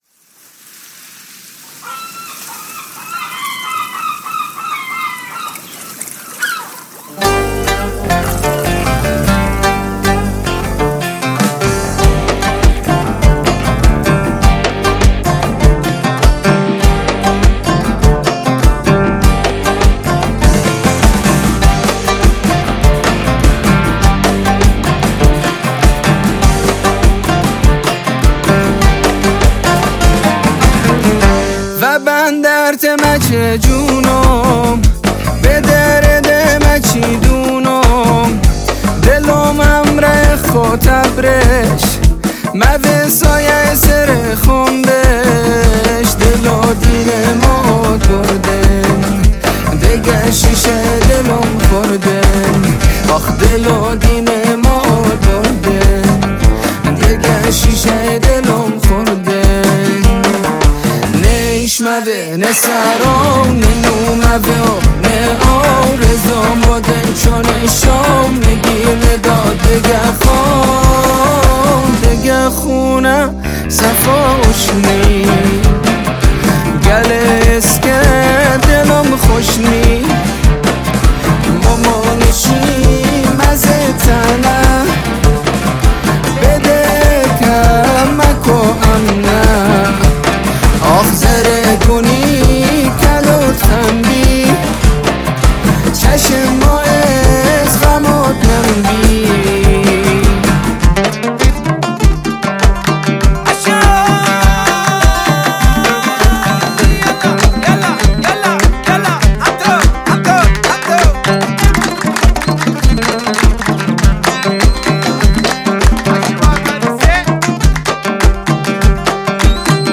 عود
در استودیو پارسینا گراش